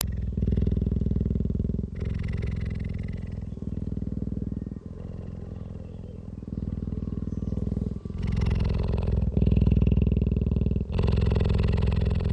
Голоса Гепардов - Звуки
cheetah5.mp3